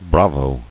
[bra·vo]